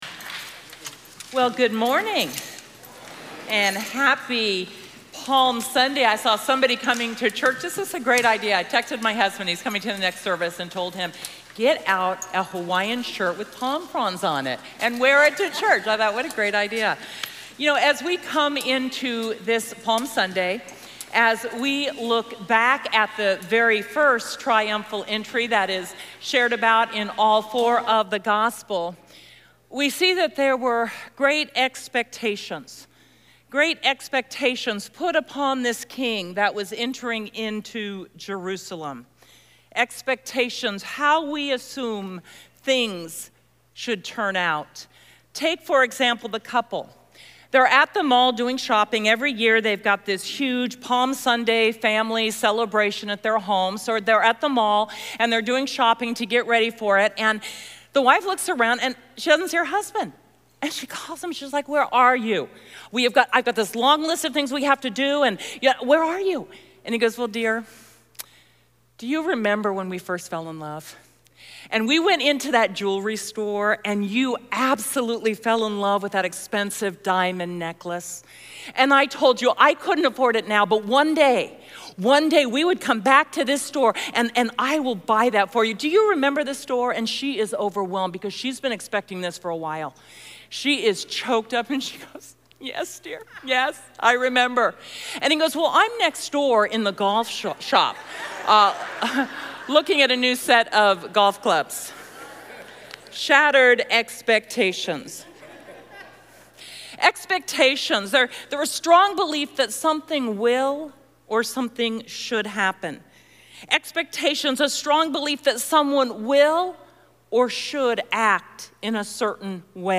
March 24, 2024 – Realigning Expectations (Message Only) – Glenkirk Church
Palm Sunday